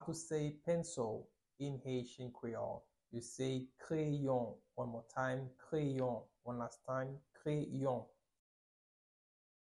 Pronunciation:
16.How-to-say-Pencil-in-haitian-creole-–-Kreyon-pronunciation-.mp3